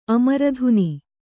♪ amaradhuni